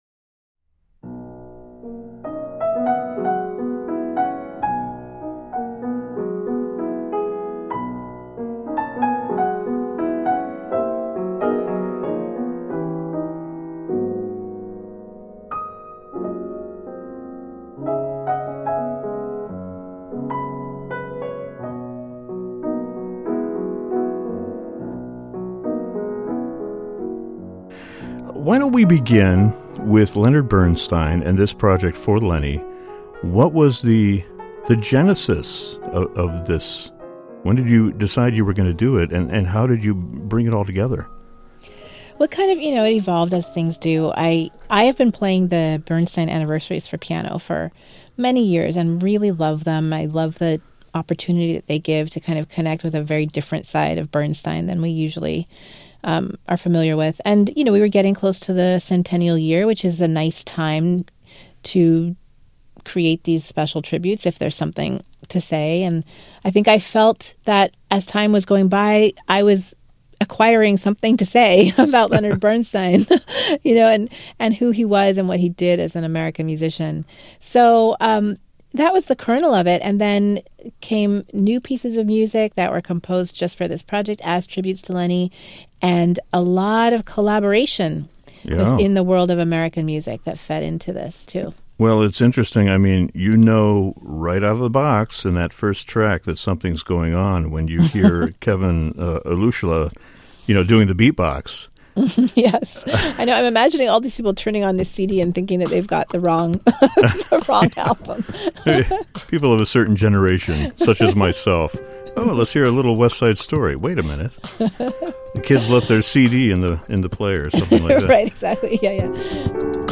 Pianist Lara Downes talks about her intimate tribute to Leonard Bernstein and his American legacy in her new album from Sony Classical, entitled For Lenny.